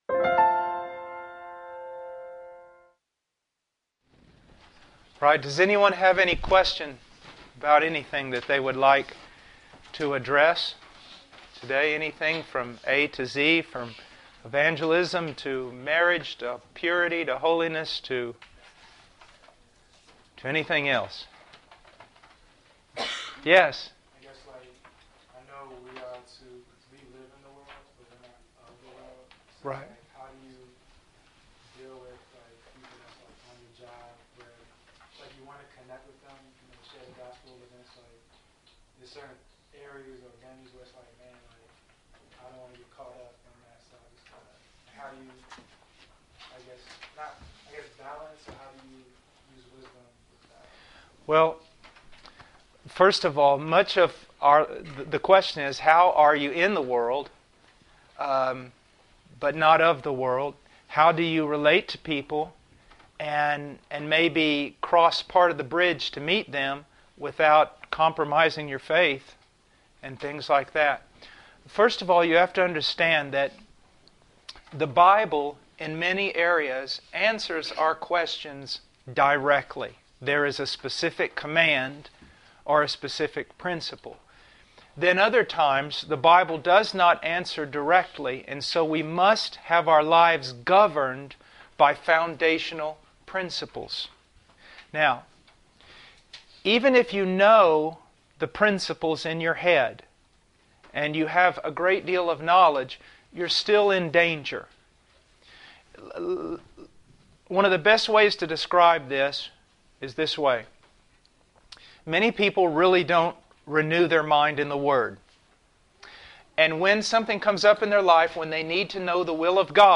Question and Answer Session